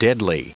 Prononciation du mot deadly en anglais (fichier audio)
Prononciation du mot : deadly